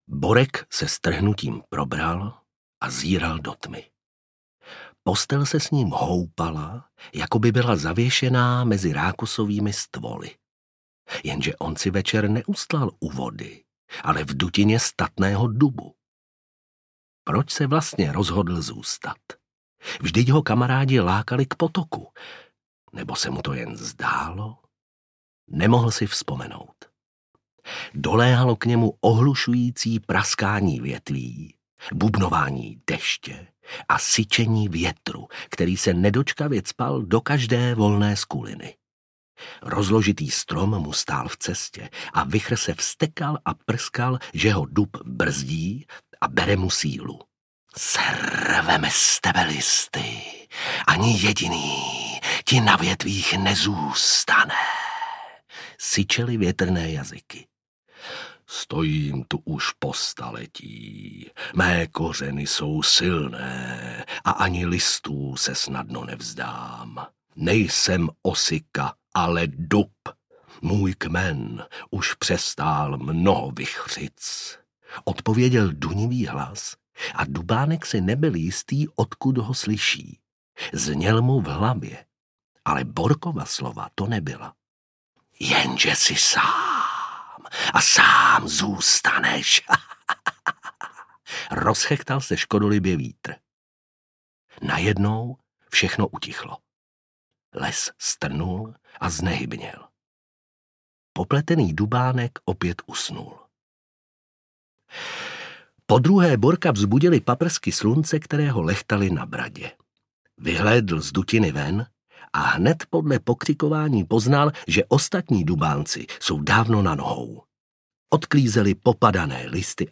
Dubánek a noční bouře audiokniha
Ukázka z knihy
Vyrobilo studio Soundguru.